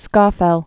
(skôfĕl)